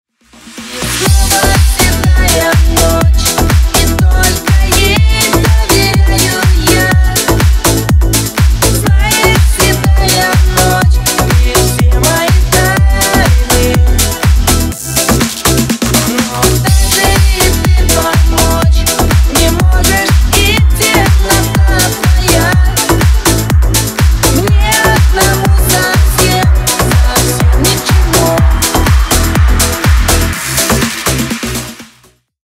Ремикс # Поп Музыка
грустные